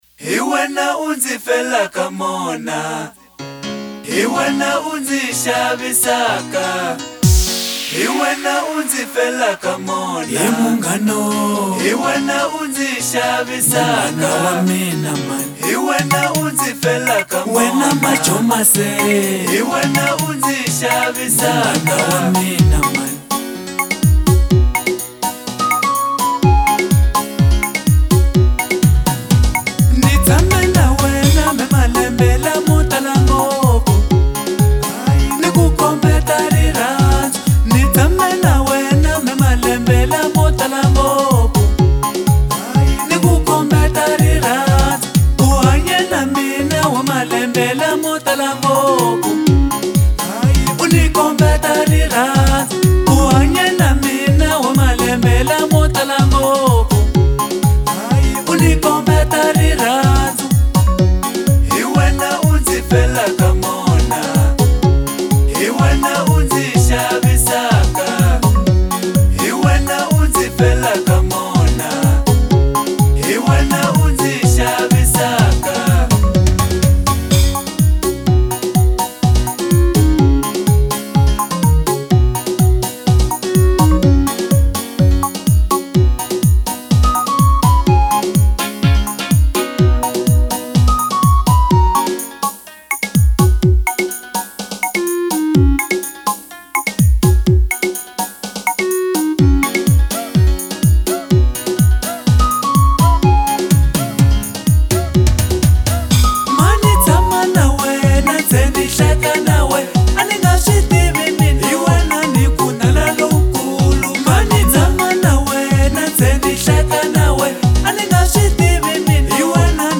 Genre : African Disco